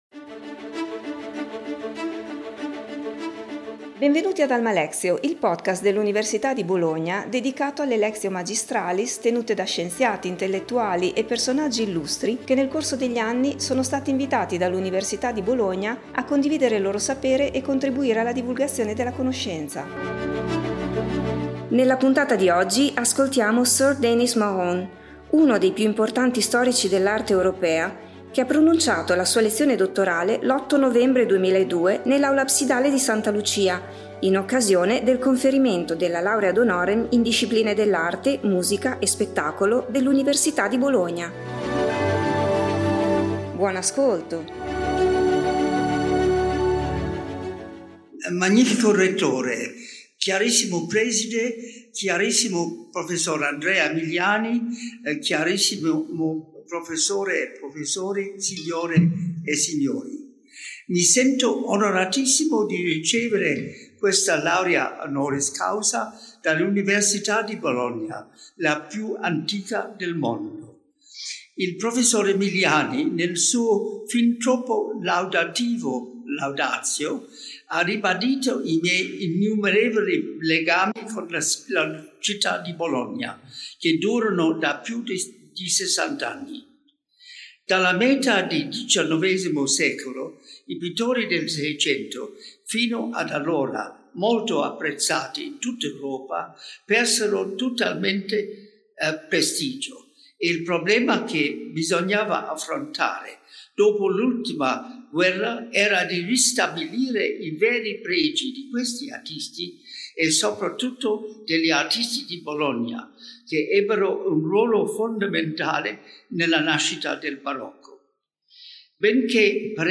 Sir Denis Mahon, uno dei più importanti storici dell’arte europea che ha lavorato a Bologna per quasi mezzo secolo, ha pronunciato la sua lectio magistralis l’8 novembre 2002 nell’Aula Absidale di Santa Lucia in occasione del conferimento della Laurea honoris causa in Discipline dell’arte, musica e spettacolo dell’Università di Bologna.